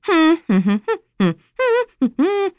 Nicky-Vox_Hum.wav